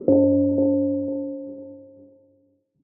UIMvmt_Game Over Ghostly Haunted 02.wav